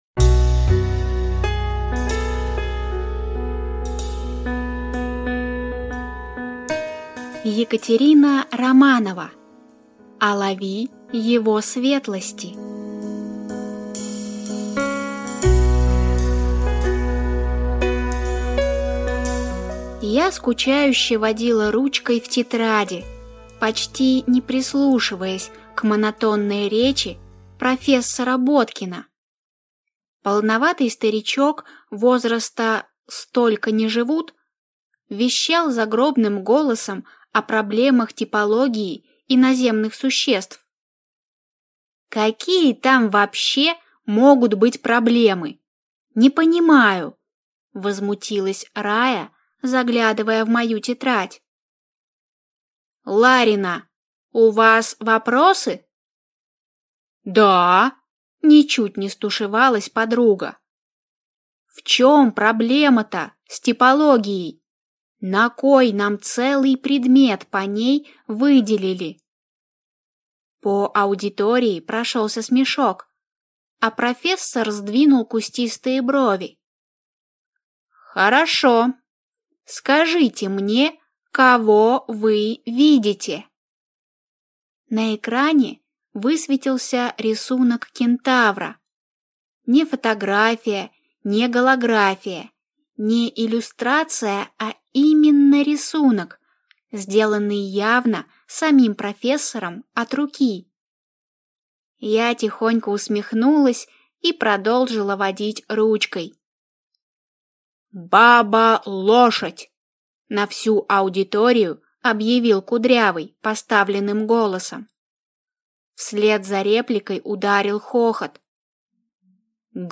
Aудиокнига Алави его светлости